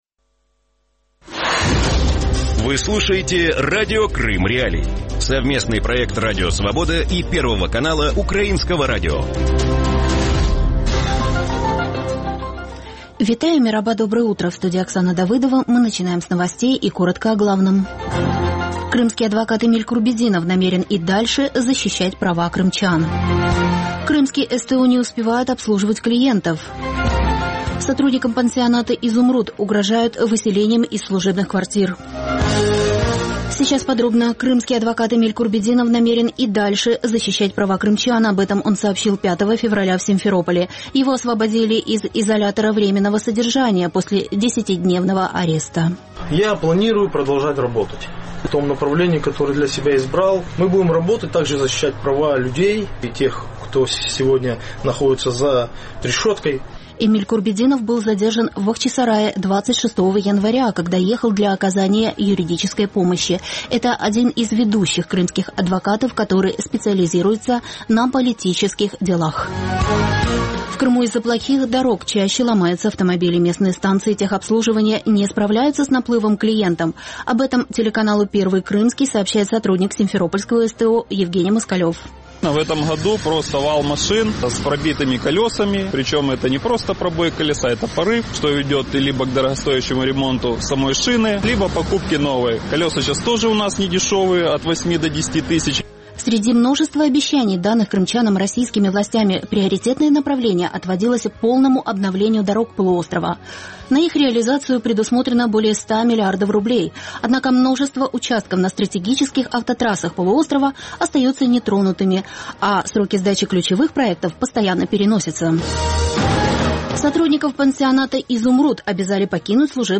Ранковий ефір новин про події в Криму. Усе найважливіше, що сталося станом на цю годину.